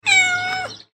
Domestic Cat Meow Sound Effect – Short, Expressive
This sound effect features a short cat meow.
It adds a simple and natural cat sound to your project.
Domestic-cat-meow-sound-effect-short-expressive.mp3